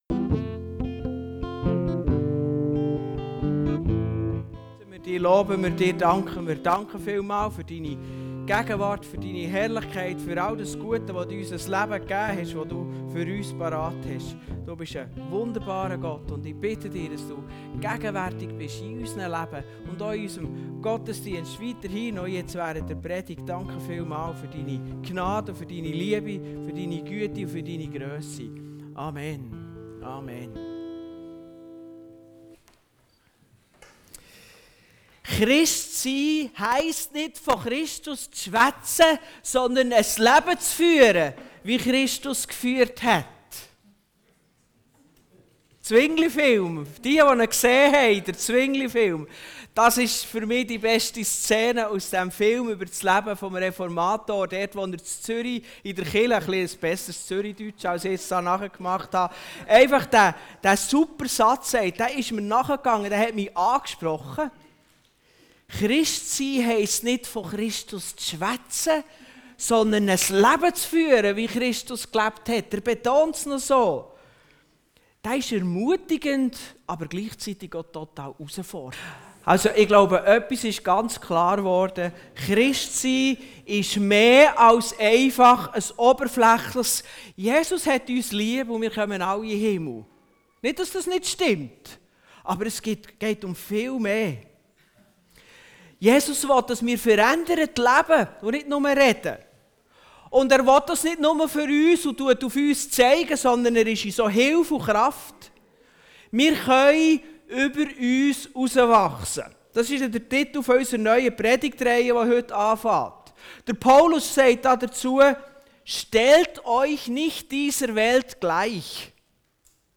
Passage: Römer 12,2 Dienstart: Gottesdienst